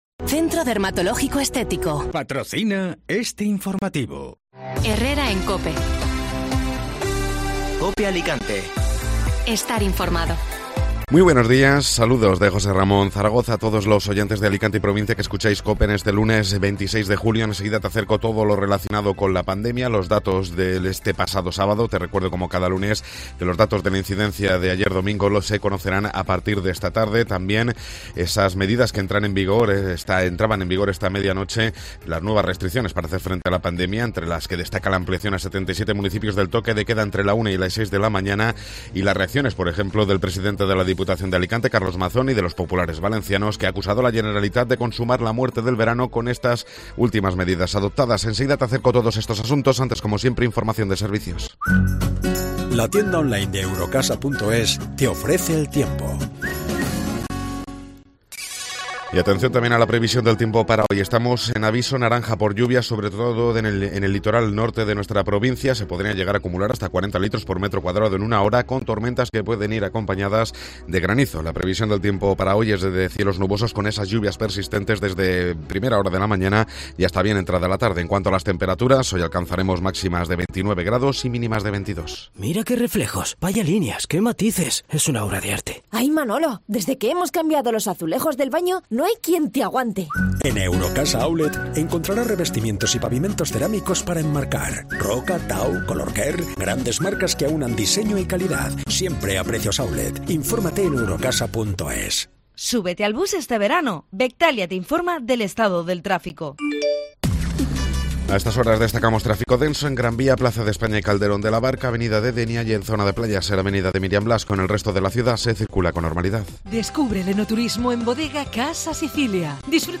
Informativo Matinal (Lunes 26 de Julio)